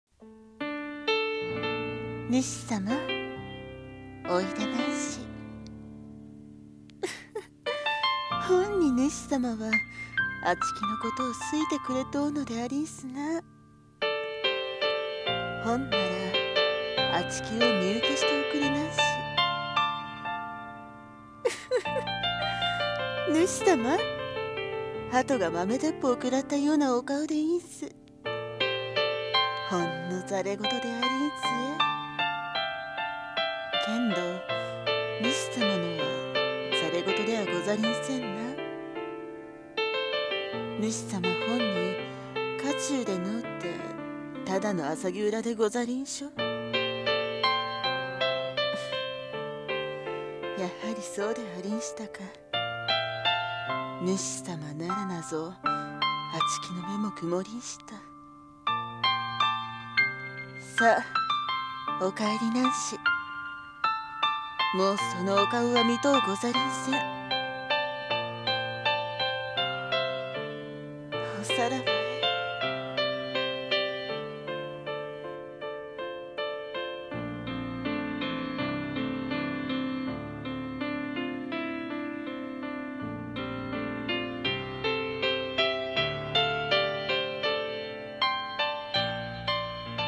…おさらばえ…【和風台本・一人声劇